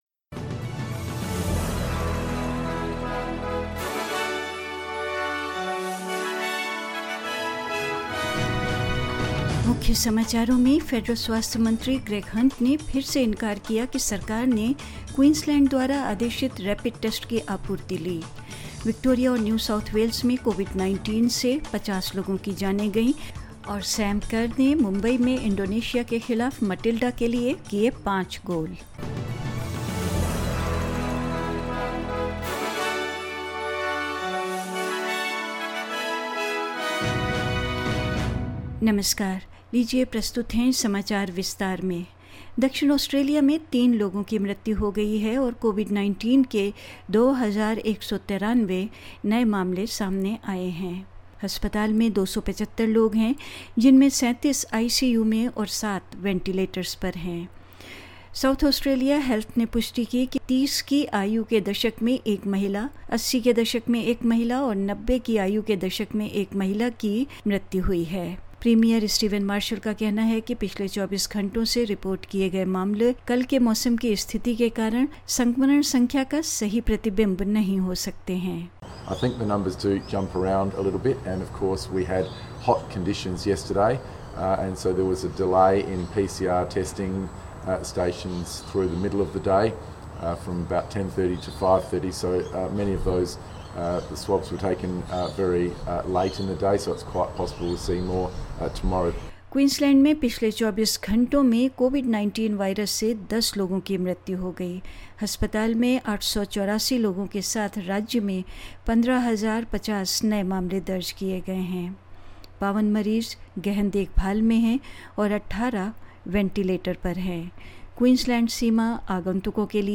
In this latest SBS Hindi bulletin: Federal health minister Greg Hunt again denies that the government took rapid test supplies ordered by Queensland; Fifty people lose their lives to Covid-19 in Victoria and New South Wales; Sam Kerr has bagged five goals for the Matildas against Indonesia in Mumbai and more news